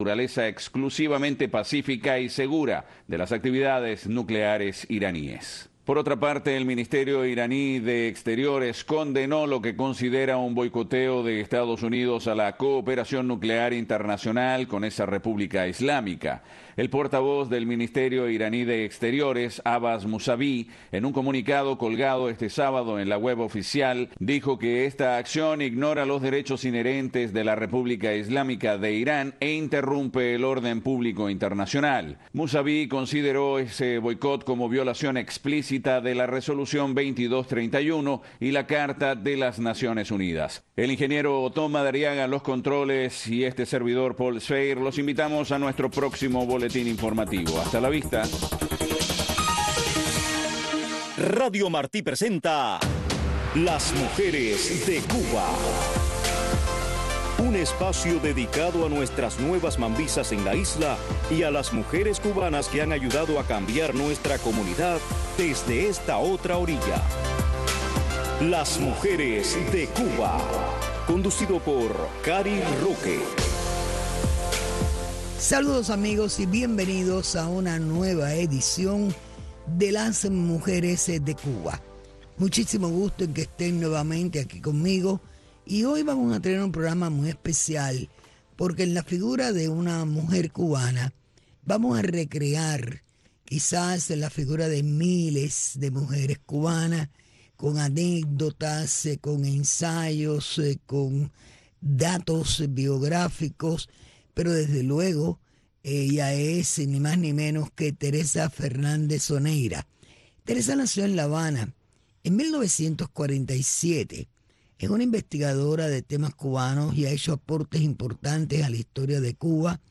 Un programa narrado en primera persona por las protagonistas de nuestra historia.